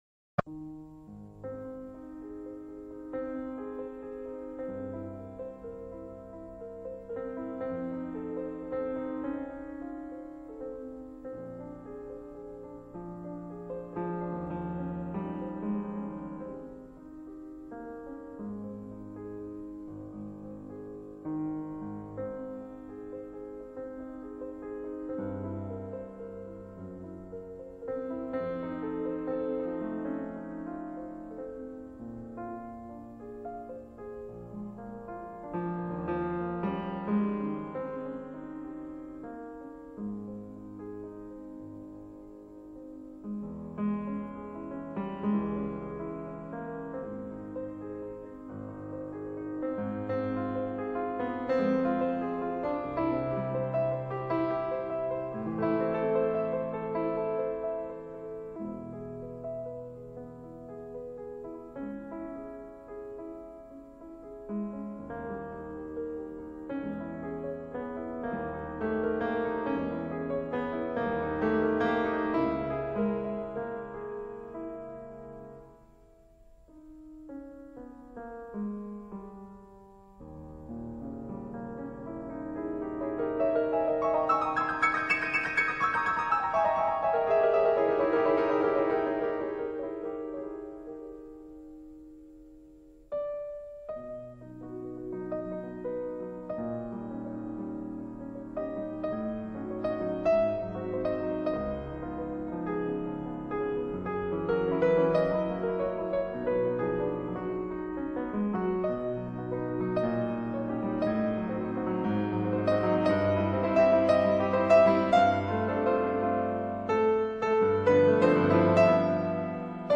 A peça está escrita em Lá b Maior e a sua dinâmica inicial é piano (doce).
Assim, seguimos para outra variação do tema principal que vai crescendo lentamente, preparando-nos para o auge da peça pautado por acordes e uma dinâmica forte.
Por último, a dinâmica diminui, aumentando somente num acorde, voltando, depois, para o pianíssimo nos acordes finais.